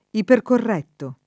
[ iperkorr $ tto ]